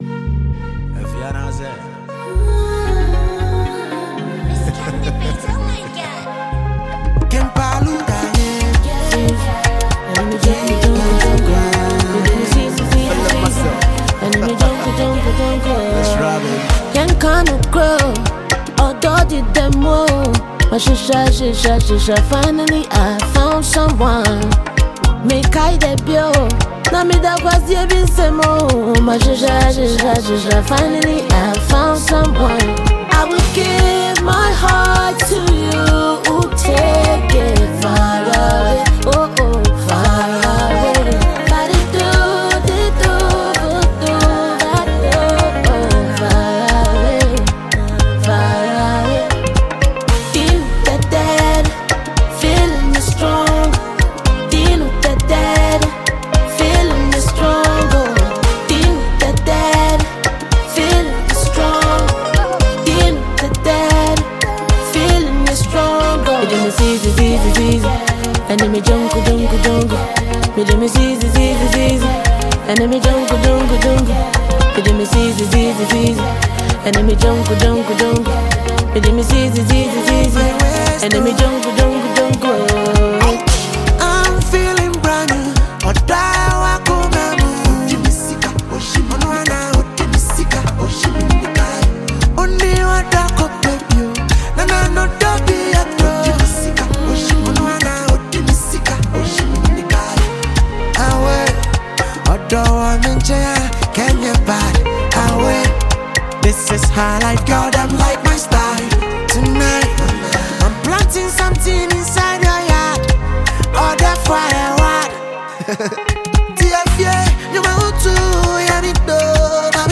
Sensational Ghanaian songstress
Genre: Afrobeats